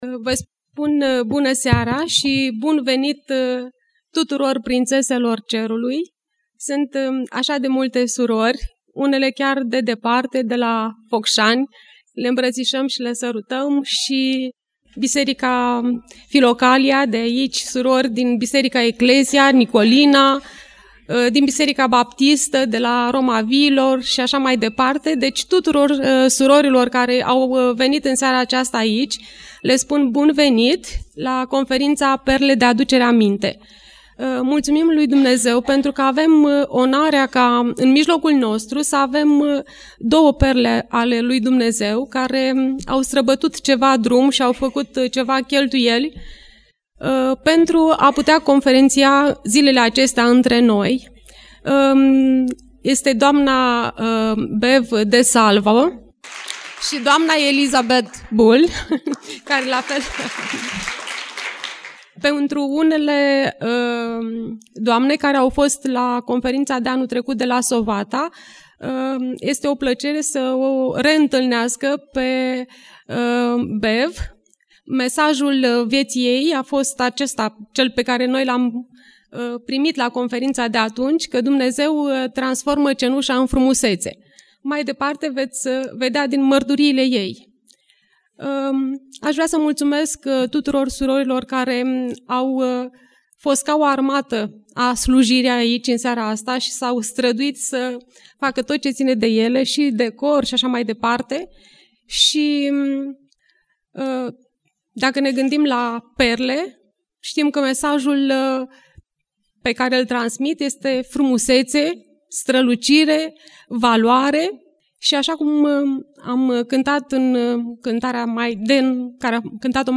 Conferinta Perle de aducere aminte : Deschiderea conferintei